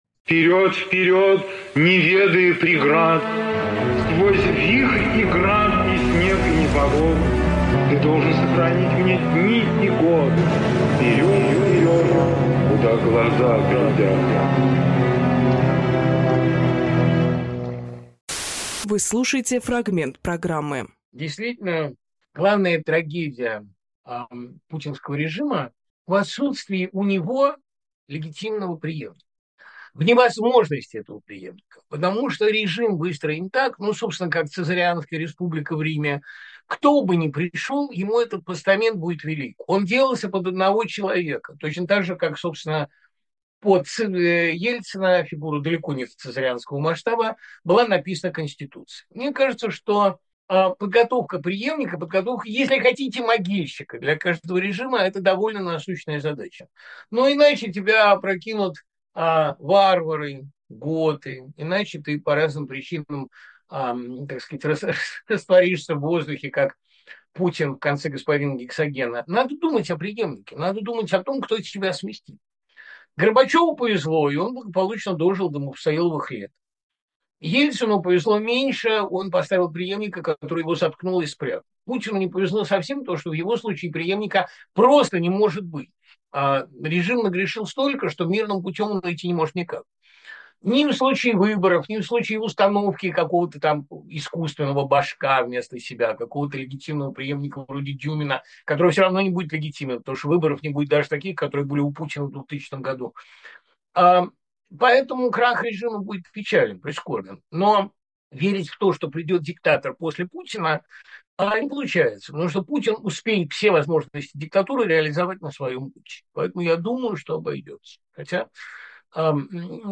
Дмитрий Быковпоэт, писатель, журналист
Фрагмент эфира от 11.12.24